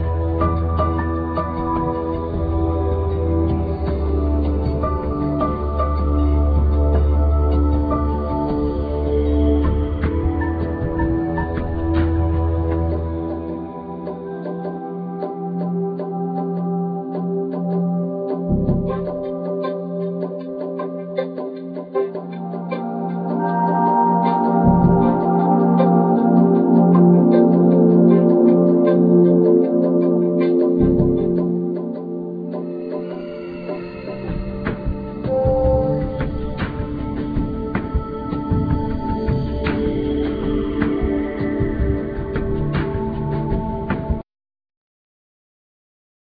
African drums
Indian drums
Accordion,Keyboards
Sitar,Surbahar,Keyboards